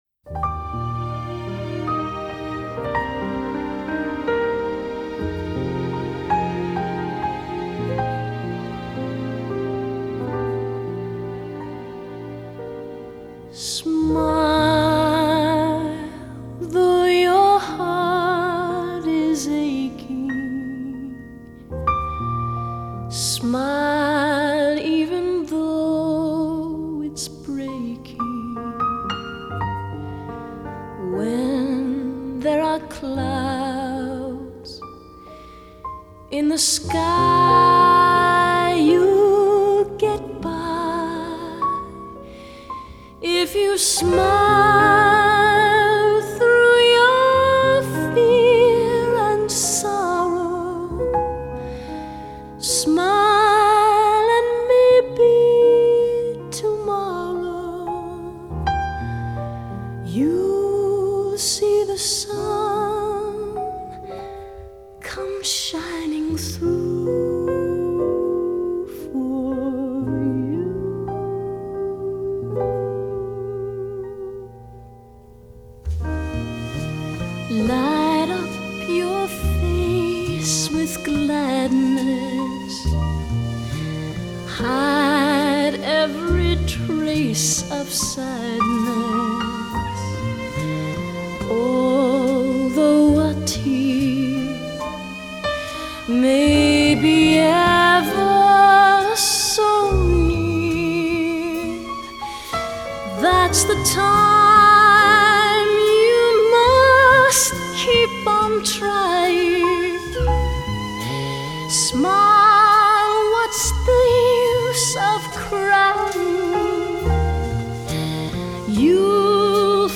英国皇家御前演出天才少女歌手